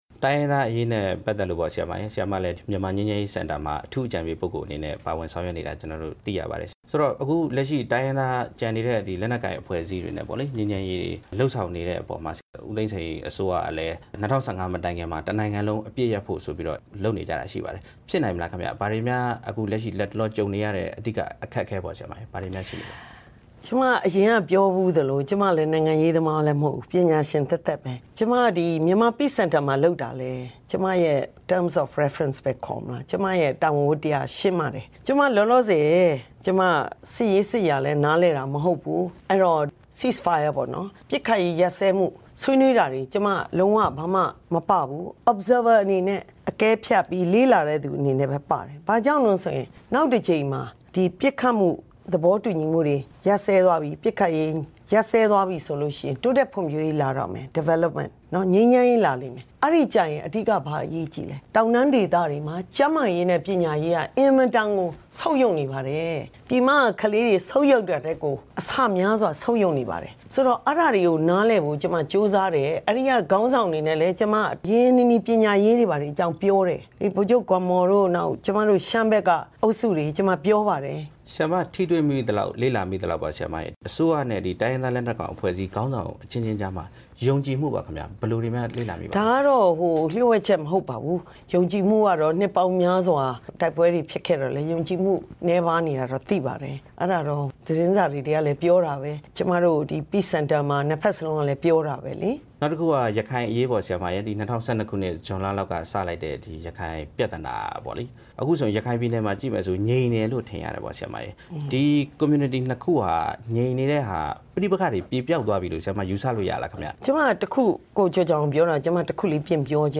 ပညာရေး ပြုပြင်ပြောင်းလဲရေး အကြံပေးခေါင်းဆောင် ဒေါက်တာ ဒေါ်ရင်ရင်နွယ်နဲ့ မေးမြန်းချက်
ဝါရှင်တန်ဒီစီမြို့တော် RFA ရုံးချုပ်မှာ